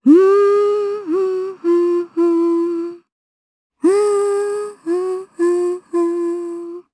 Kirze-Vox_Hum_jp_b.wav